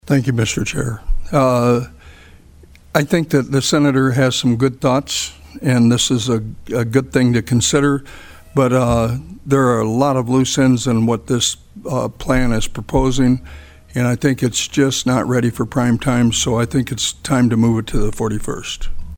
Senator Perry made a motion to refer it to the 41st day to kill the bill and explain why.